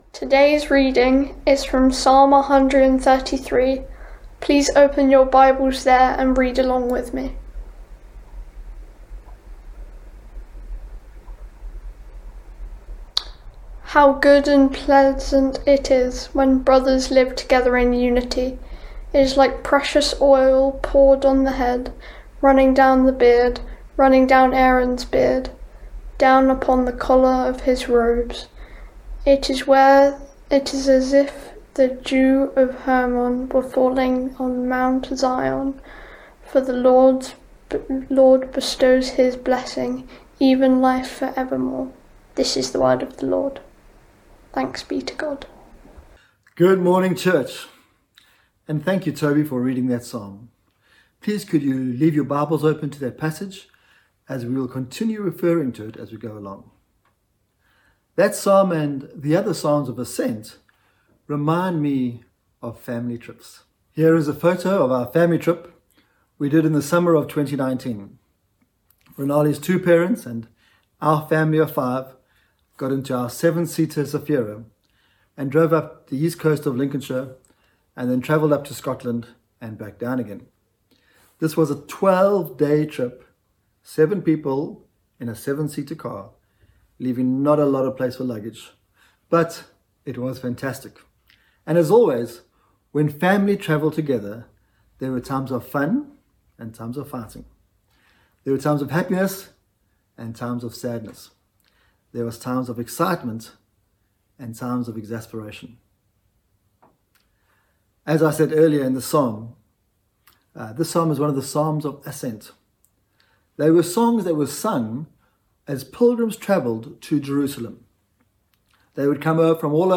Service Type: Streaming